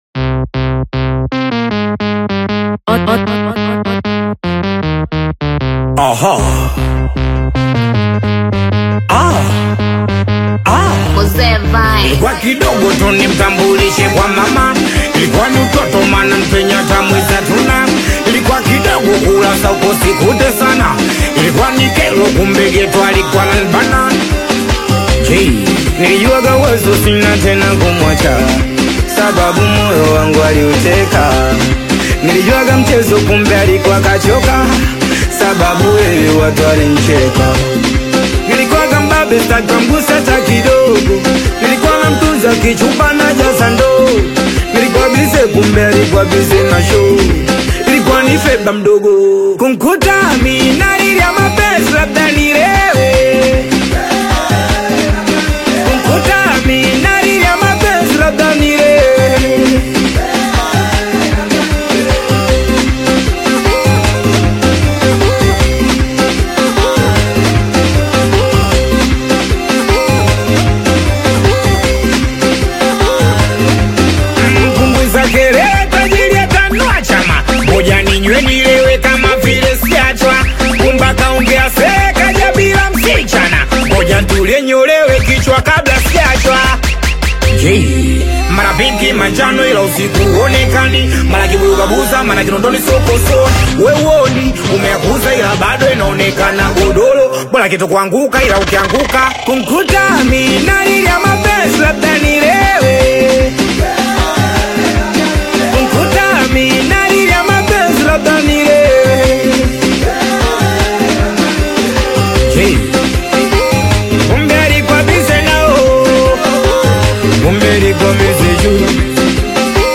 is an energetic Tanzanian Singeli/Bongo Flava single
lively delivery and authentic urban sound